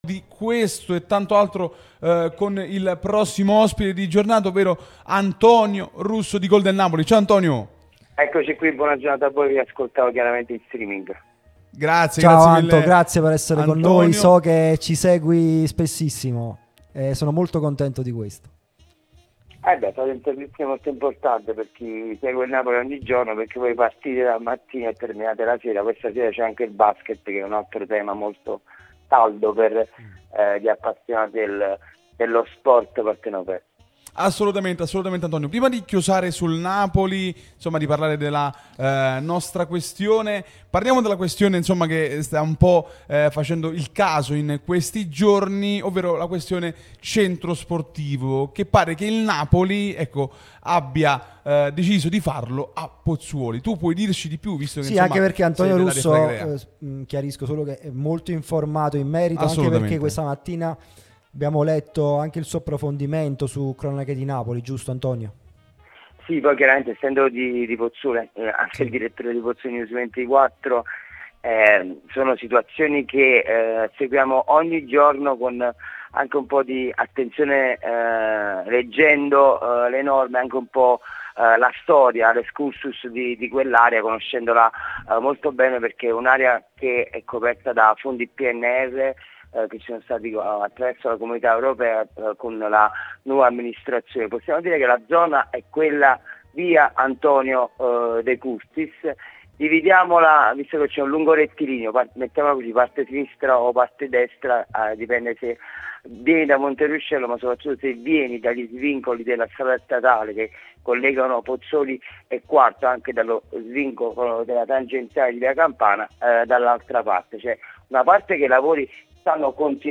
l'unica radio tutta azzurra e live tutto il giorno